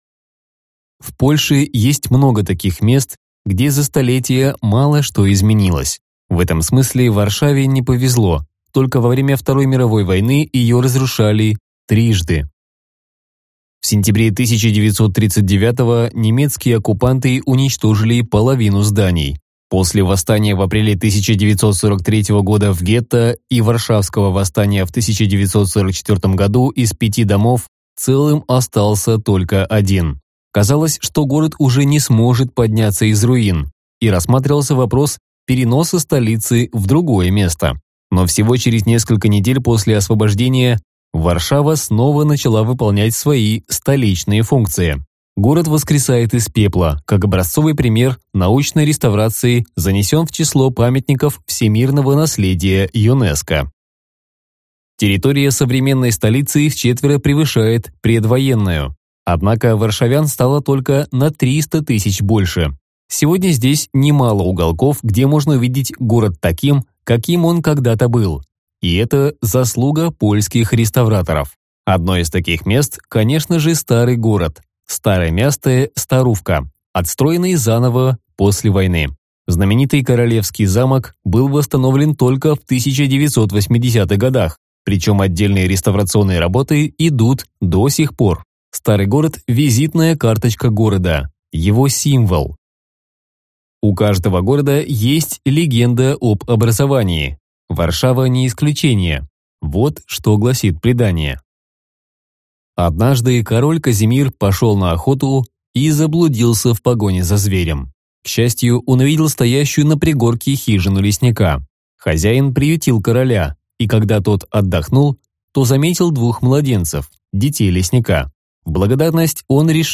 Аудиокнига Варшава | Библиотека аудиокниг